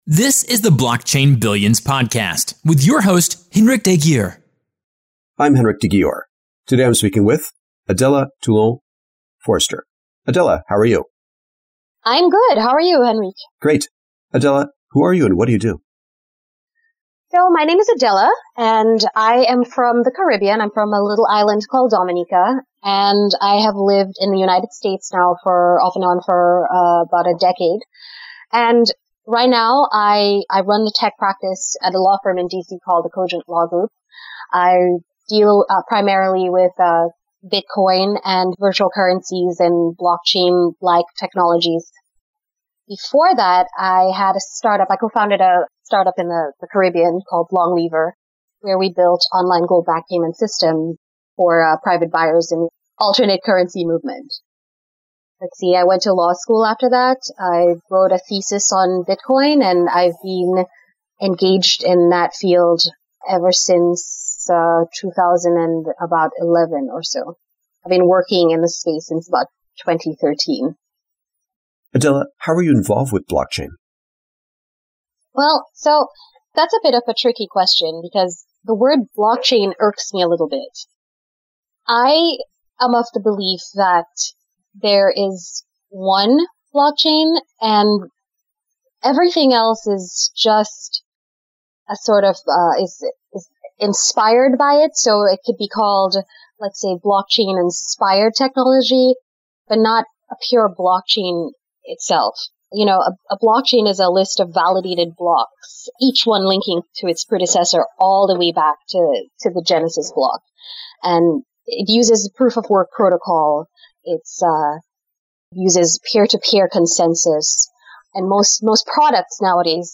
Blockchain Billions interview